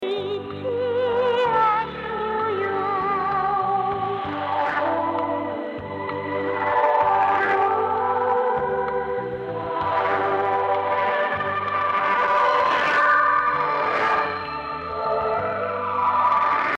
Все записи с линейного выхода.
Много "артефактов" при приеме :)
Тут коротенькая запись АМ станции:
В AM и SSB на слух всё более-менее чисто.